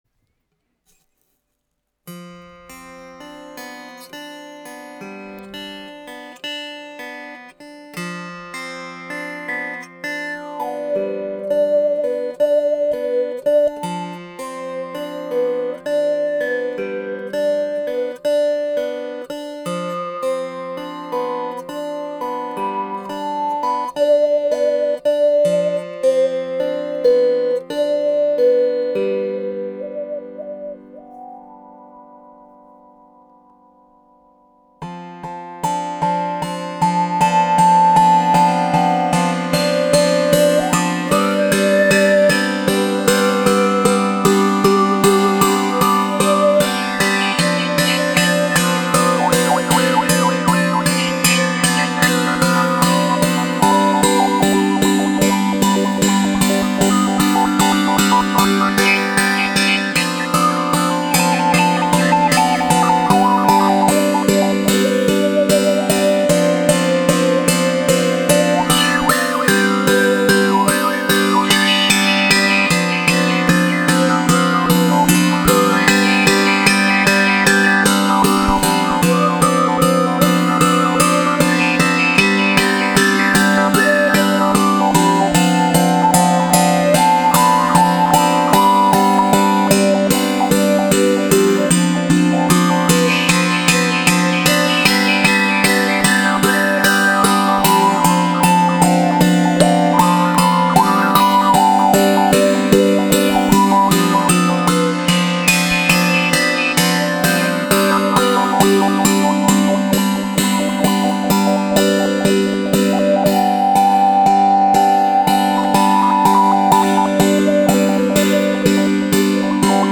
COSMICBOW 5 CORDES avec cuillère harmonique
Le son est très chaud et attrayant…entrainant.
Les sons proposés ici sont réalisés sans effet.